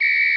Whistle Hi Sound Effect
Download a high-quality whistle hi sound effect.
whistle-hi.mp3